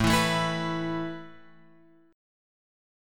A Suspended 2nd